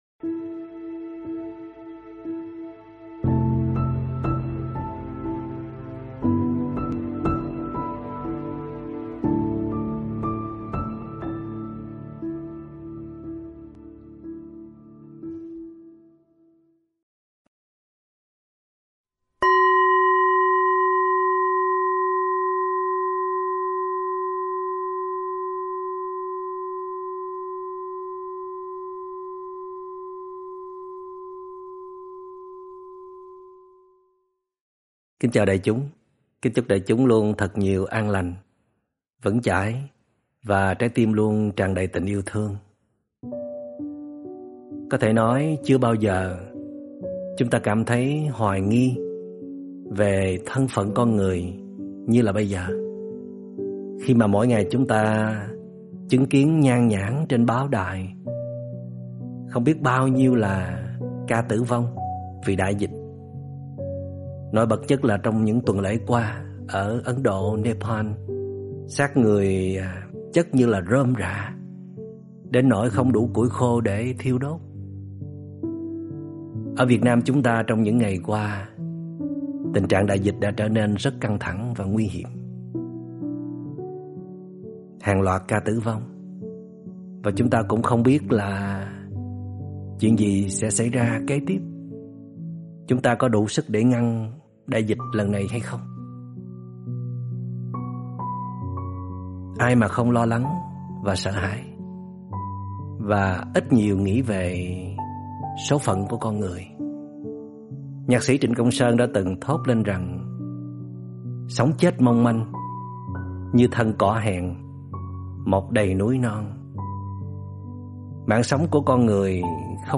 Pháp âm Bảo hộ nhau trước biến động bằng năng lượng phước đức được Trích Radio